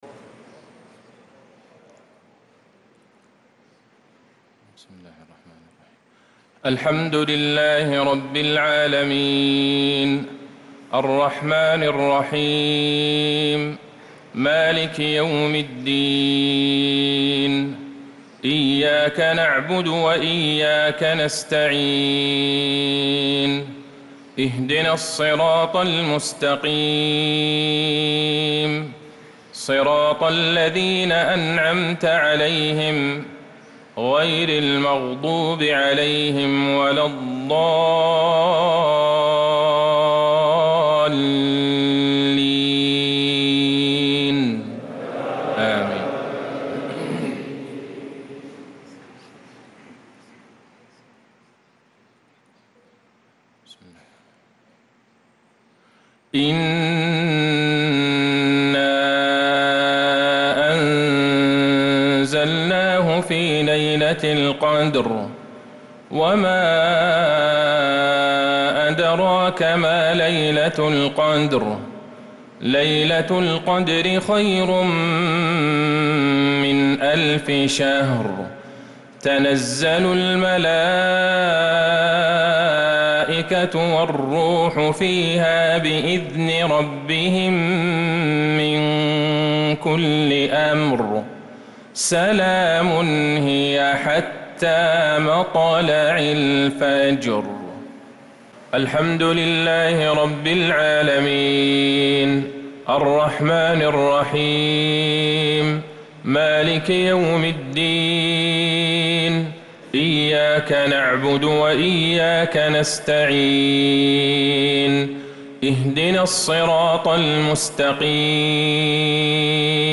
صلاة المغرب للقارئ عبدالله البعيجان 7 ربيع الآخر 1446 هـ
تِلَاوَات الْحَرَمَيْن .